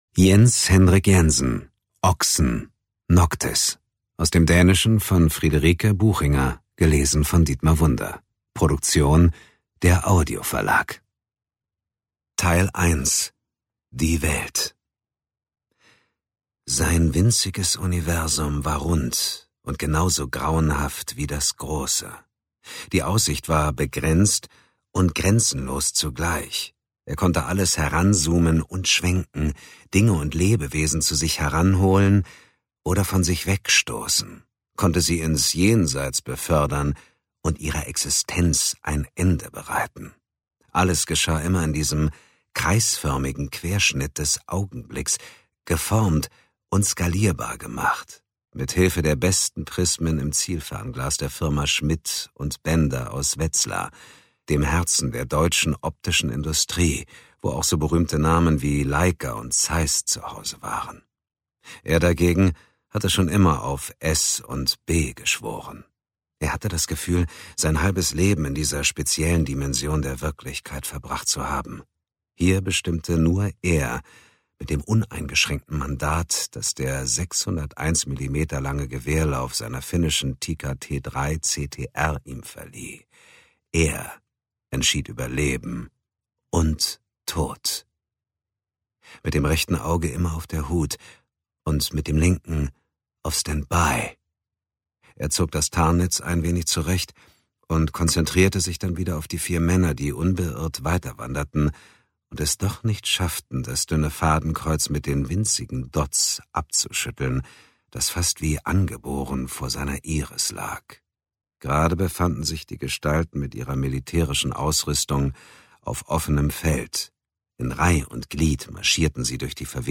Dietmar Wunder (Sprecher)